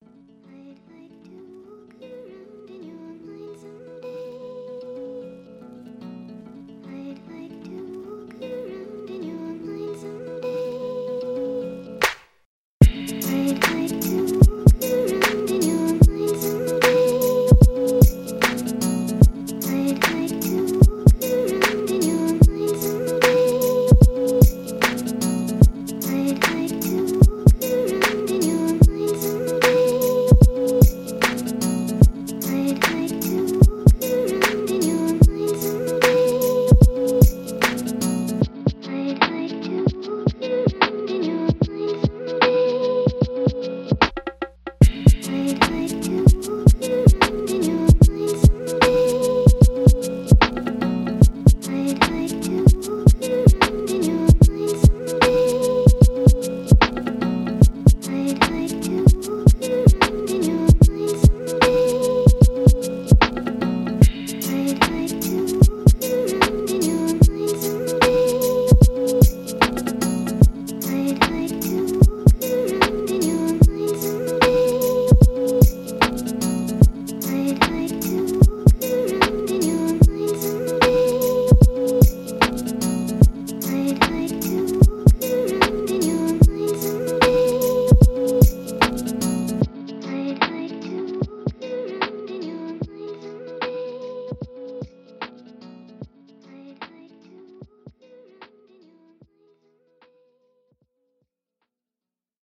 Лофьи музыка с женским вокалом из аниме